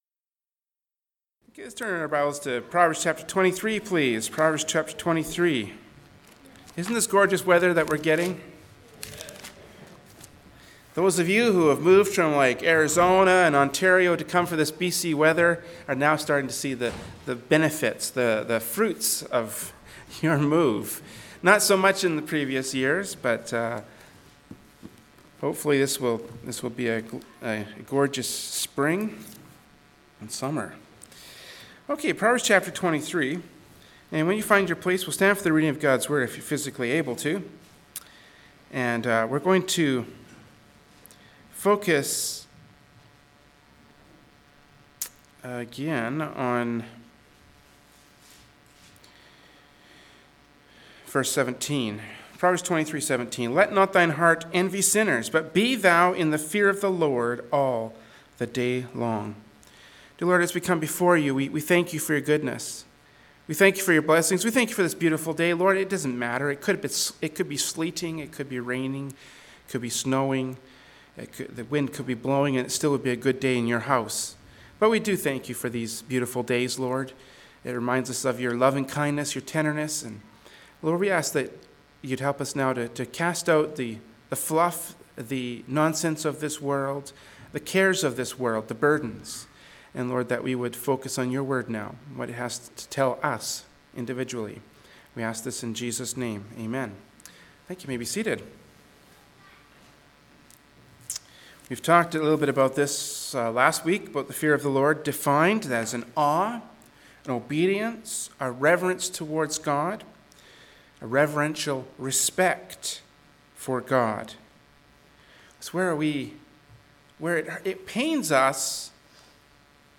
Genre: Preaching.
Service Type: Adult Sunday School